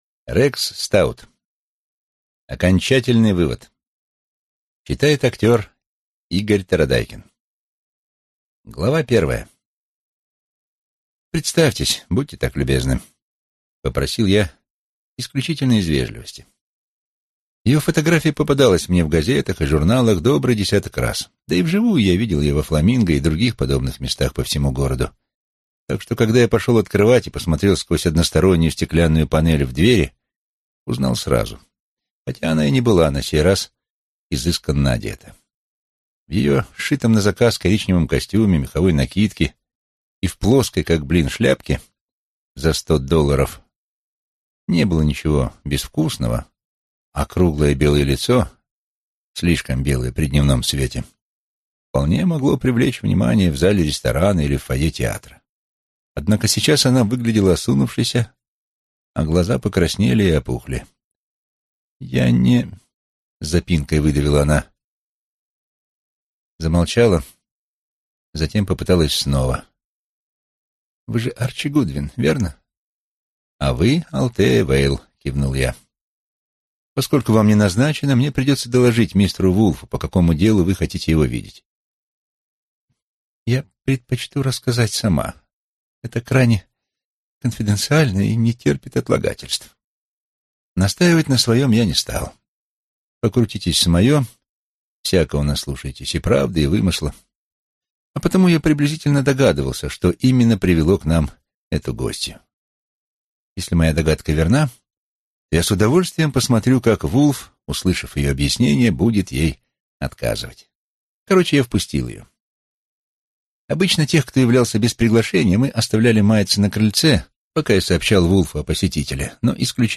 Аудиокнига Окончательный вывод | Библиотека аудиокниг